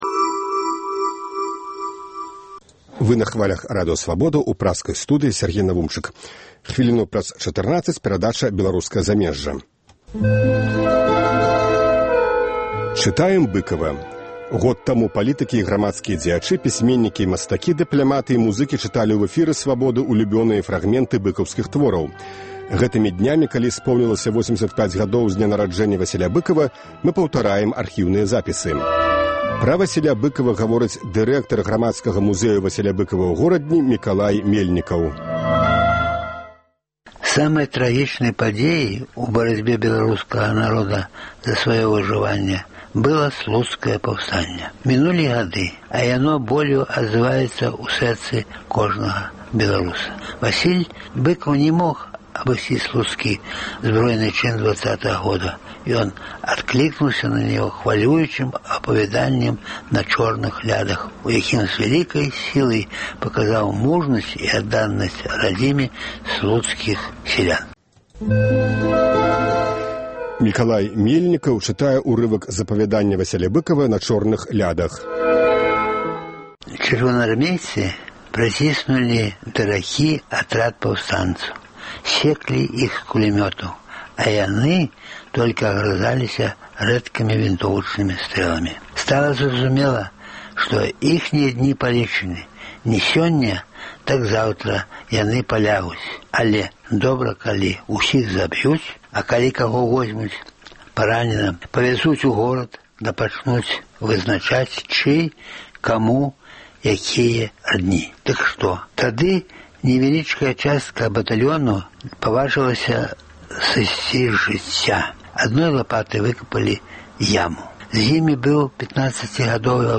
Вядомыя людзі Беларусі чытаюць свае ўлюбёныя творы Васіля Быкава.
Былы амбасадар Ізраілю ў Беларусі Зэеў Бэн-Ар'е чытае фрагмэнт з апавяданьня "Бедныя людзі".